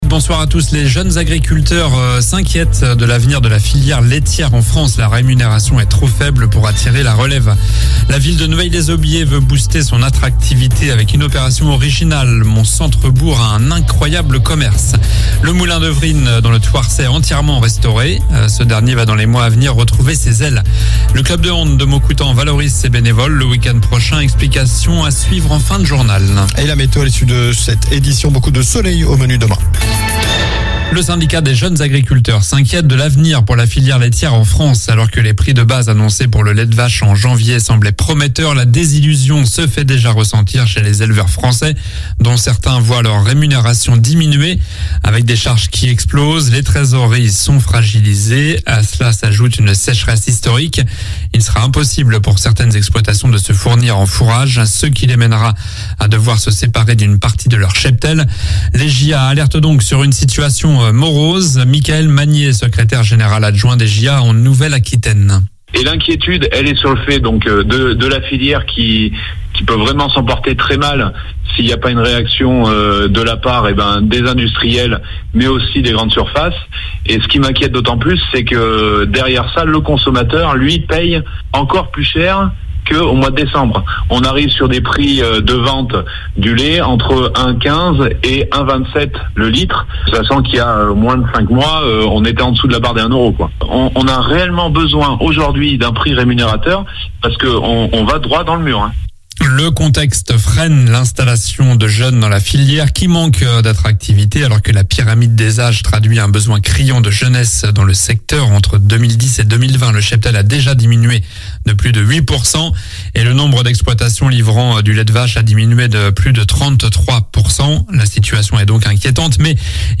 Journal du mardi 18 avril (soir)